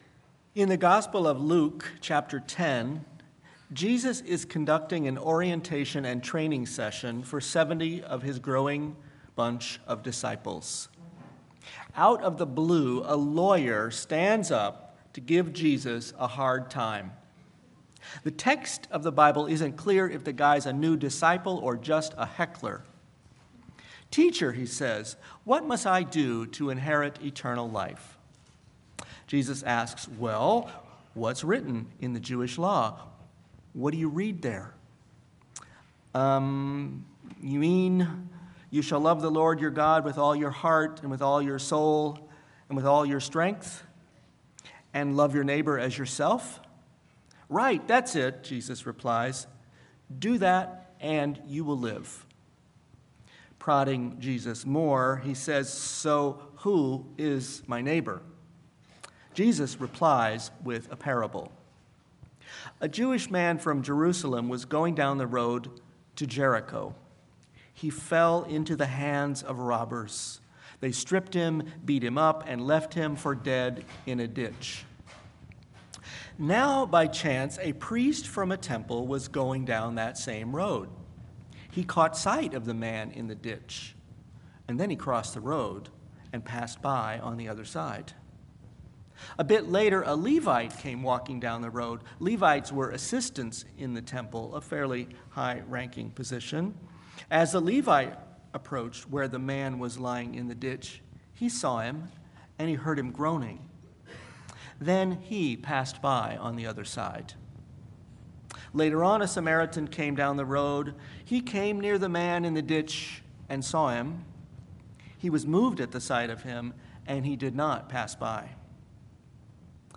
Sermon-Ironies-of-Life-Legacy-of-Jesus.mp3